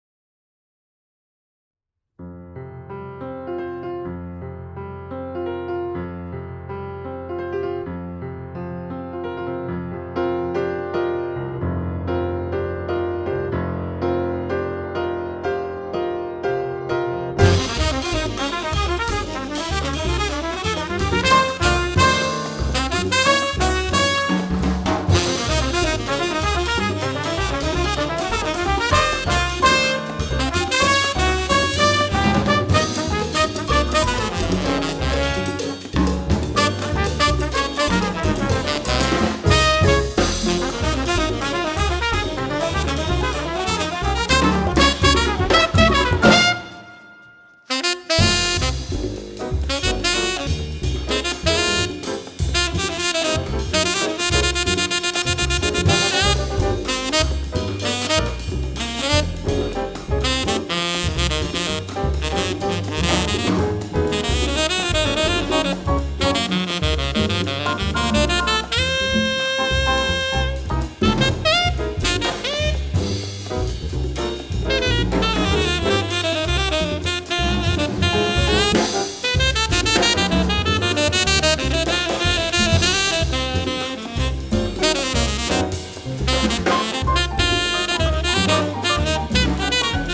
klavir
tolkala
kontrabas
trobenta
bobni